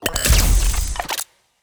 LASRGun_Electron Impeller Fire_07.wav